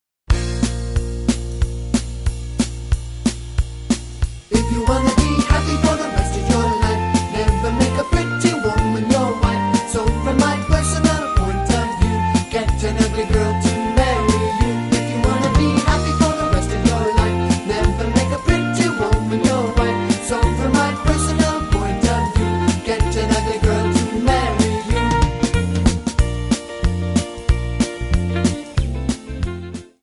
Backing track files: 1960s (842)
Buy With Backing Vocals.